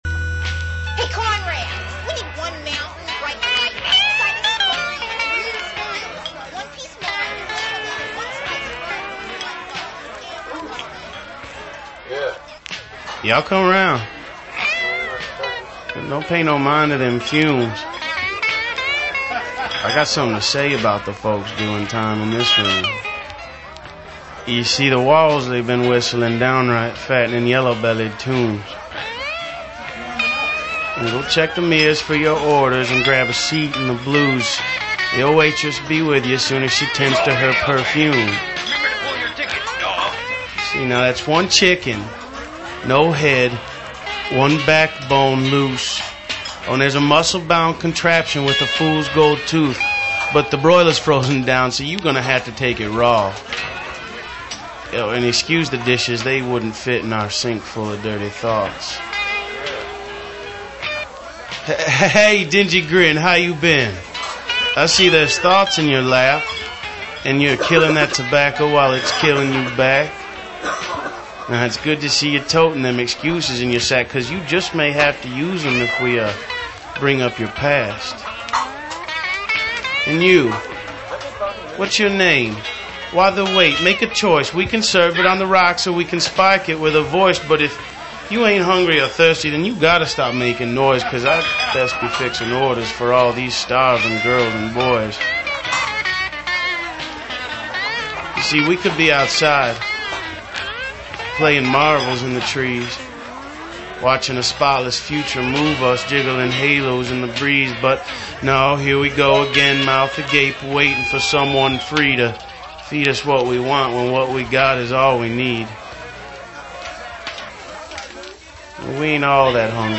He's equally intense on this album.